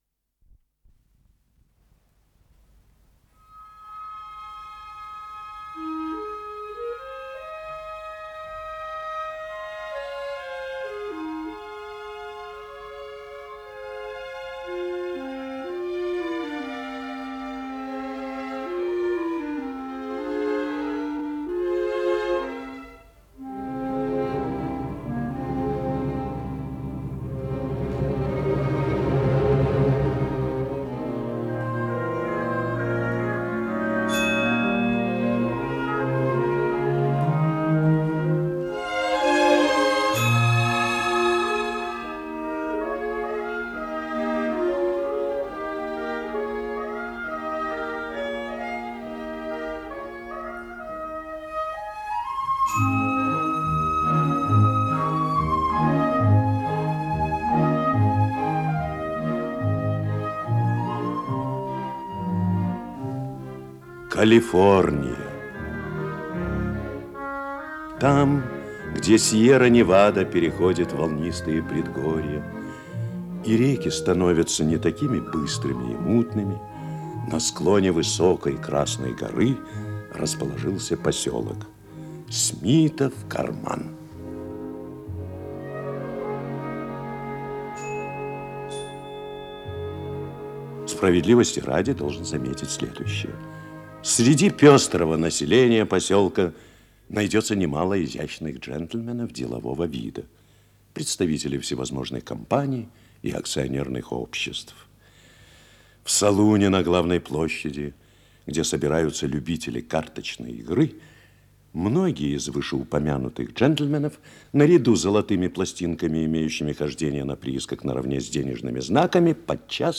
Исполнитель: Артисты московских театров
Радиопостановка